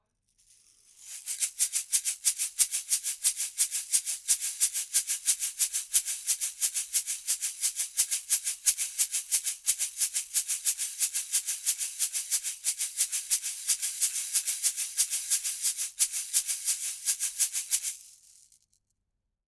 Daraus resultiert ein sehr transparentes, detailliertes Klangabbild.
Shaker – Neumann TLM 107
Das Neumann TLM 107 klingt sehr edel, wenn auch mit einer recht starken Präsenzbetonung